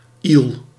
Ääntäminen
IPA: /il/